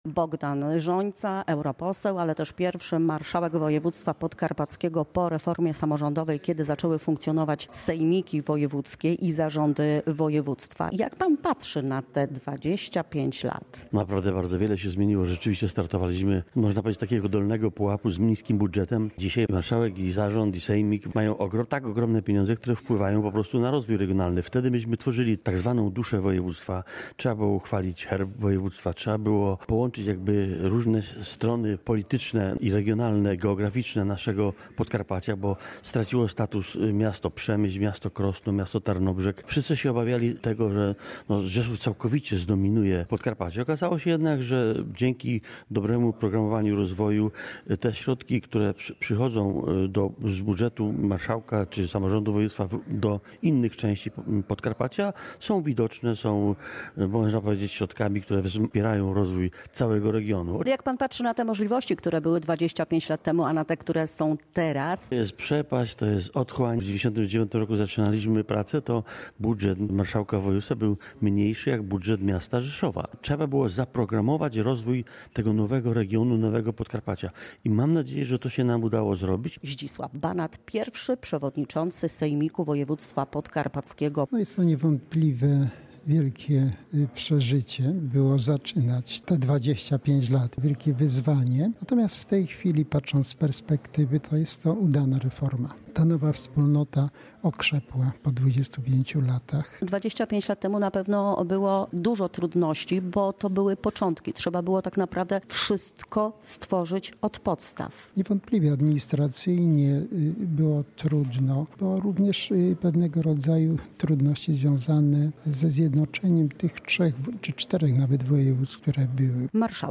W Muzeum Zamku w Łańcucie odbyła się uroczysta sesja podkarpackiego sejmiku wojewódzkiego.